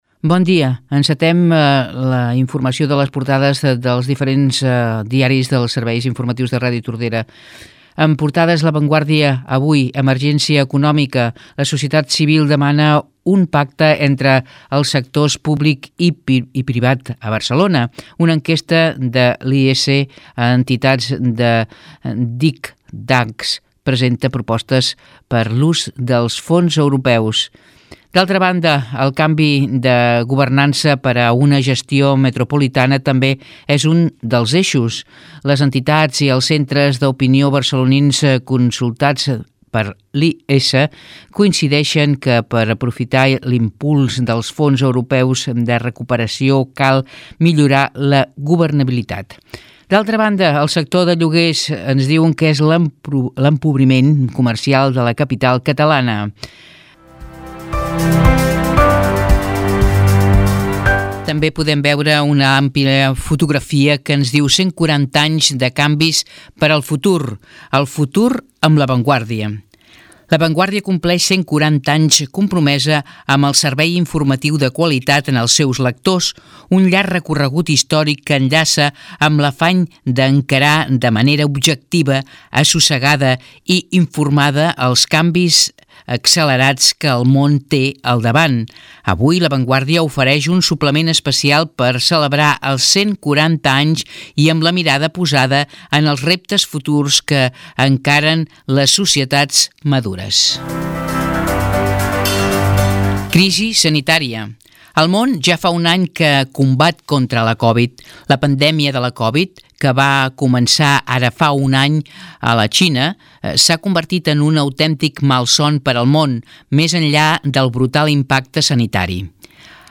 Informatius: resum de titulars - Ràdio Tordera, 2021